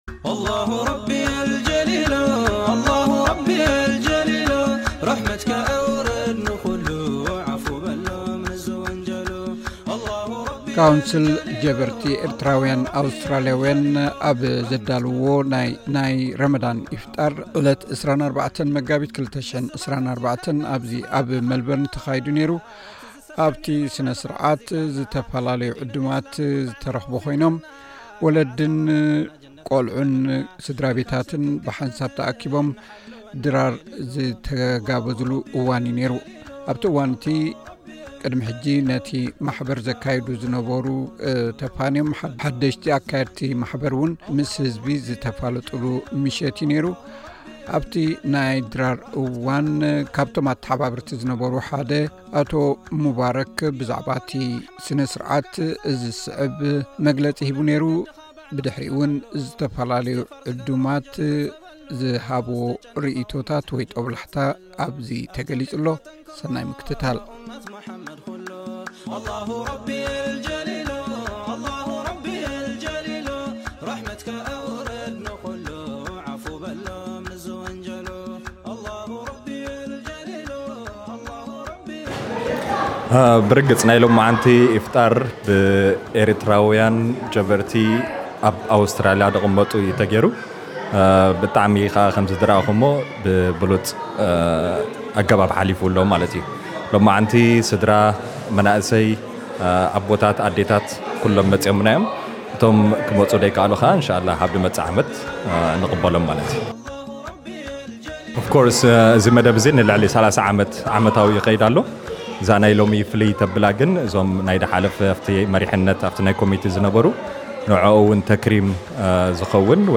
ካውንስል ኤርትራዊያን ኣውስትራሊያዊያን ጀበርቲ ዓመታዊ ረመዳን ኢፍጣር ኣብ ኣዳራሽ ኮሊንግዉድ ብ24 መጋቢት 2024 ኣካይዱ። ነቲ ስነስርዓት ዝምልከት ሓጺር ጸብጻብ።